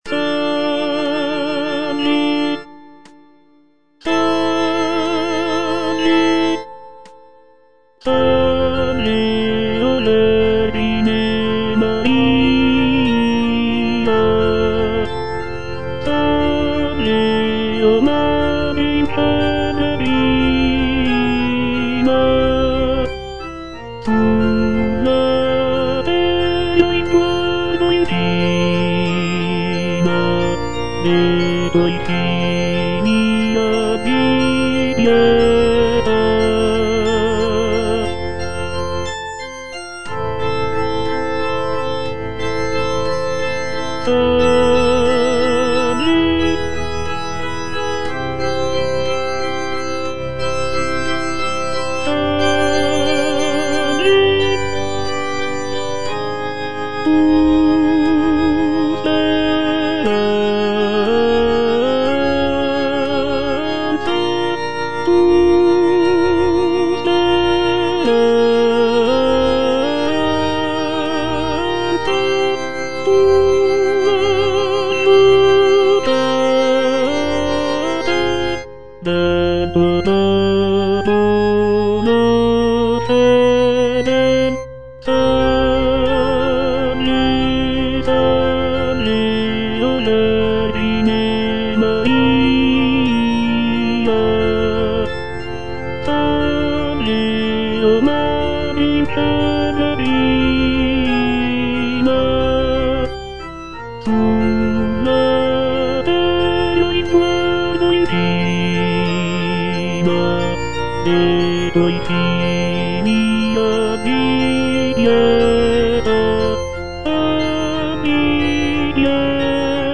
G. ROSSINI - SALVE O VERGINE MARIA Tenor (Voice with metronome) Ads stop: auto-stop
"Salve o vergine Maria" is a choral piece composed by Gioachino Rossini in 1831.
The music is characterized by its serene and devotional atmosphere, with lush harmonies and expressive melodies.